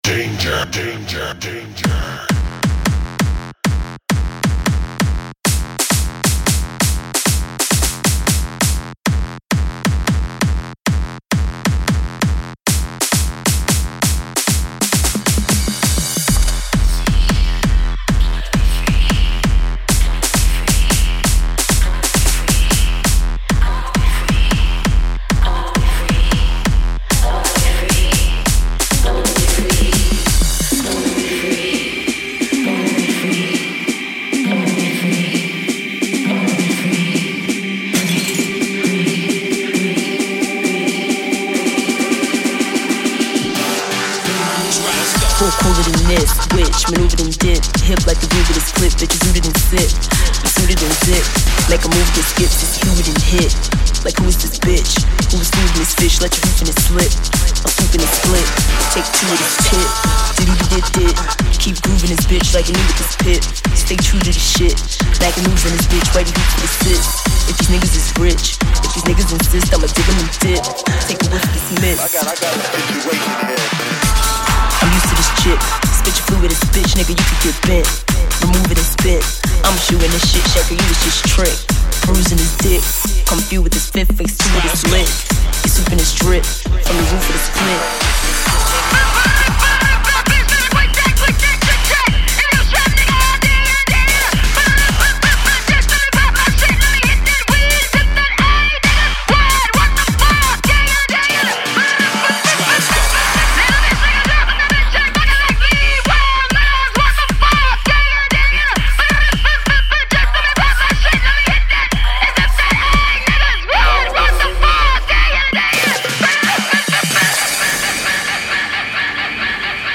музыка фон